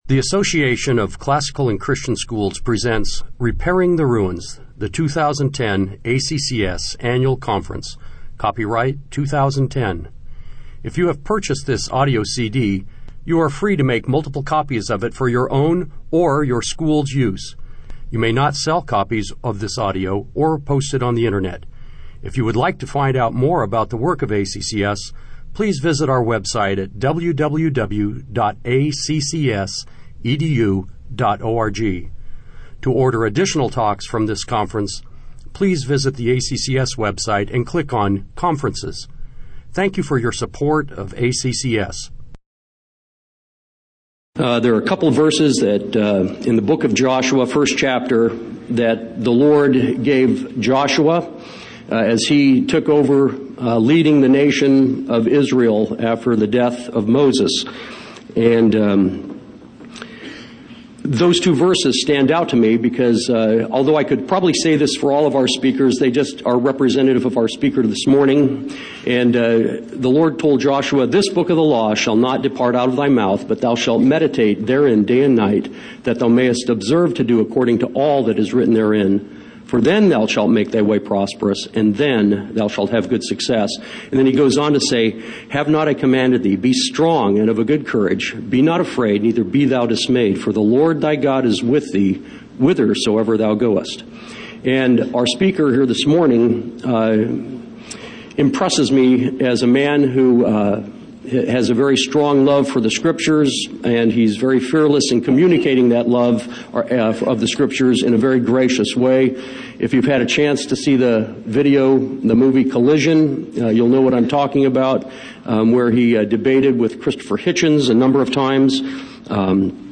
2010 Plenary Talk | 0:56:52 | All Grade Levels, Virtue, Character, Discipline
Jan 27, 2019 | All Grade Levels, Conference Talks, Library, Media_Audio, Plenary Talk, Virtue, Character, Discipline | 0 comments